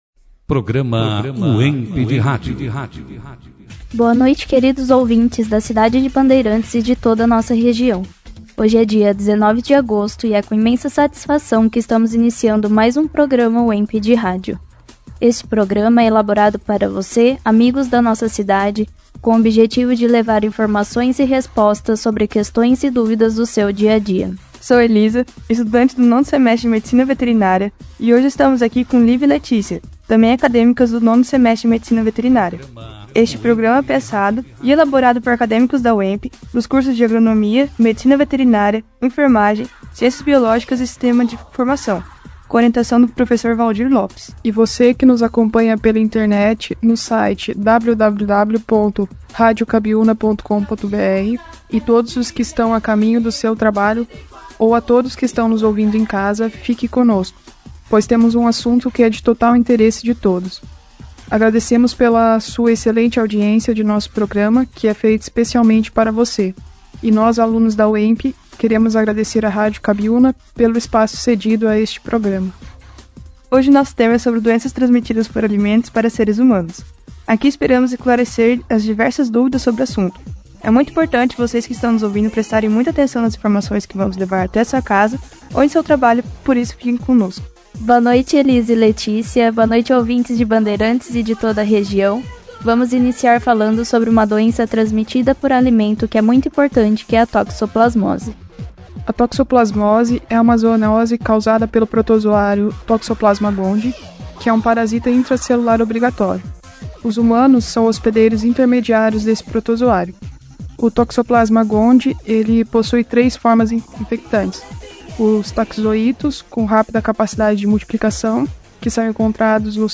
Produzido e apresentado pelos alunos, Acadêmicos do 5º ano do curso Medicina Veterinária